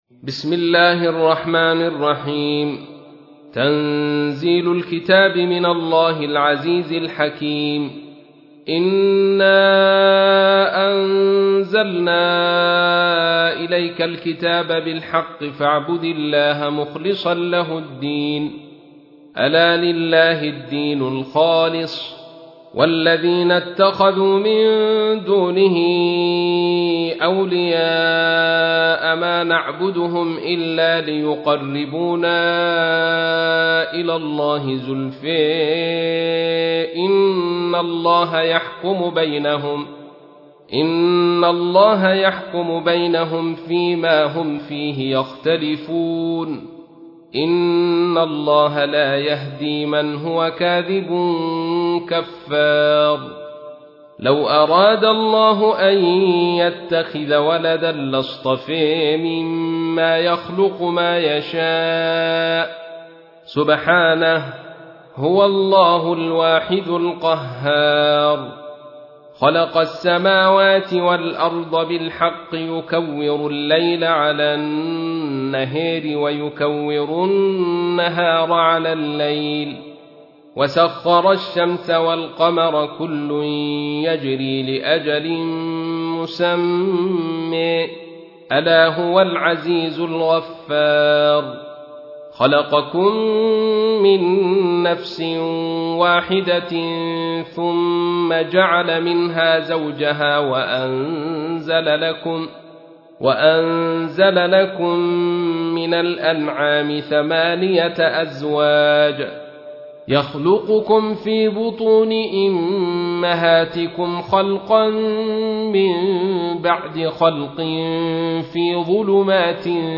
تحميل : 39. سورة الزمر / القارئ عبد الرشيد صوفي / القرآن الكريم / موقع يا حسين